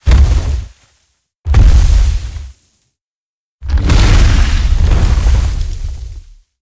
Sfx_creature_iceworm_poke_long_01.ogg